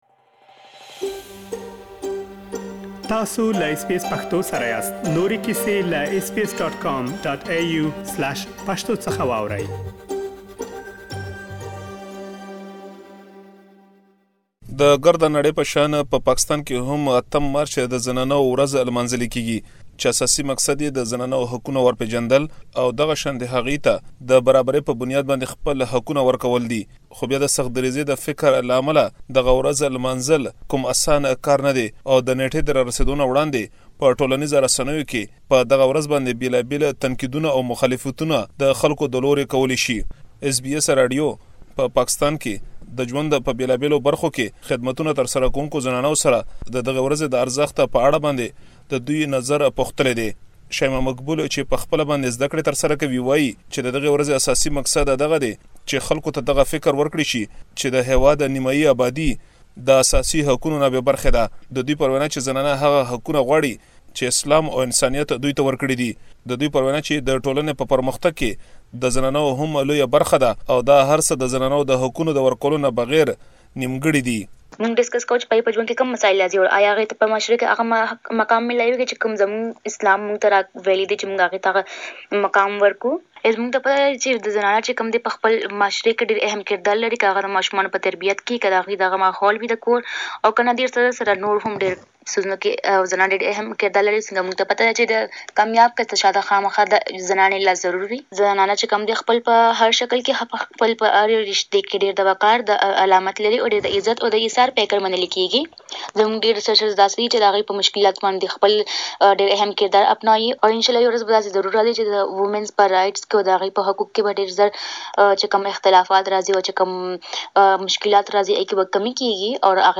داچې پښتنو ميشتو سيمو کې د ښځو حقونو پر حالت او هم پرمختګ پوه شي نو تيار شوی رپوټ واورئ.